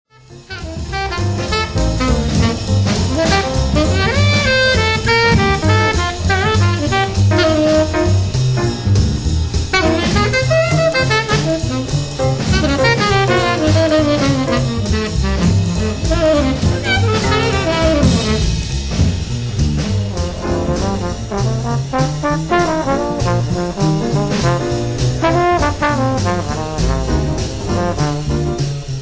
Recorded in New York, April 4, 1957